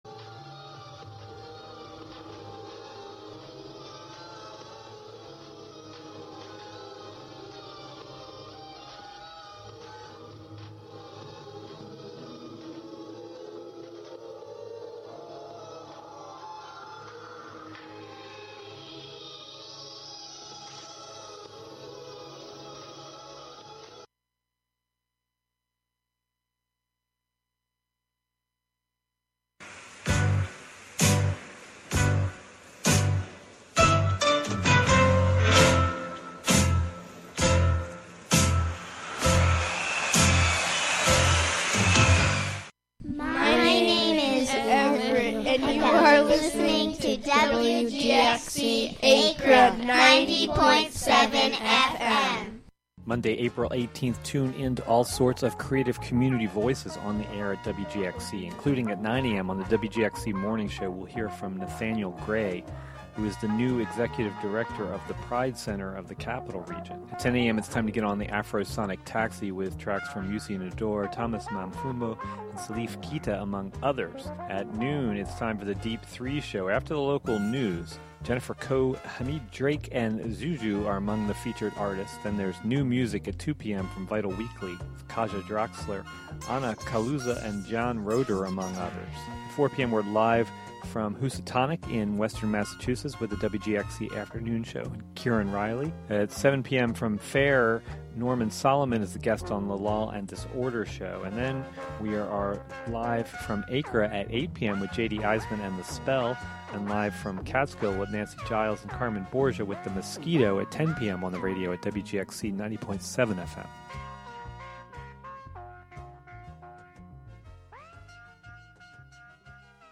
Contributions from many WGXC programmers.
WGXC's Hudson Valley Congressional Report tracks the votes, speeches, positions, fundraising, and appearances of the representatives in Congress from the Hudson Valley, and the candidates who want to replace them in November. The "WGXC Morning Show" is a radio magazine show featuring local news, interviews with community leaders and personalities, reports on cultural issues, a rundown of public meetings and local and regional events, with weather updates, and more about and for the community, made mostly through volunteers in the community through WGXC.